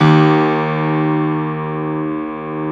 53d-pno03-D0.wav